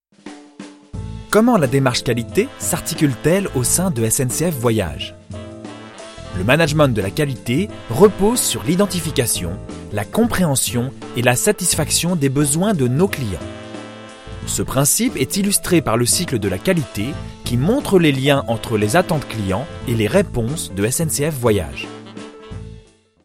Voix off masculine naturelle, posée, souriante, sensuelle
Naturelle
Voix off grave naturelle